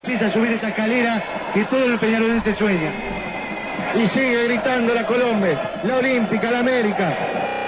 Los más importantes relatos del año del quinquenio